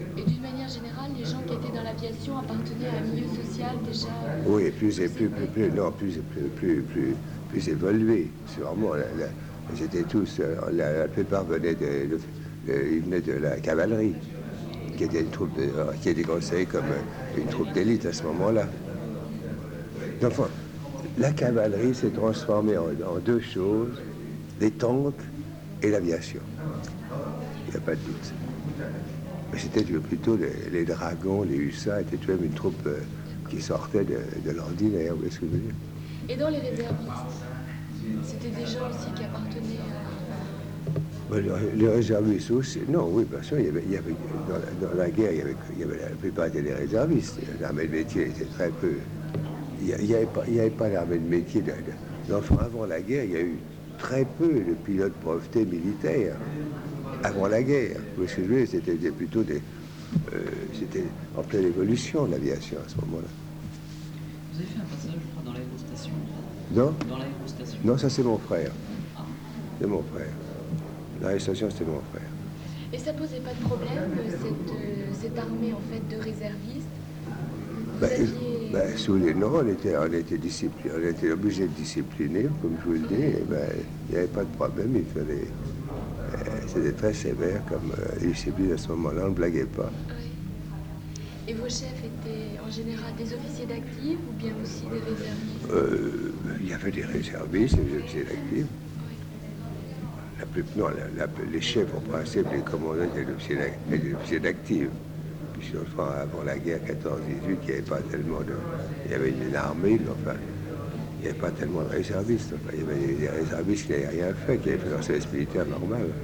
Entretien réalisé le 25 novembre 1975 à Paris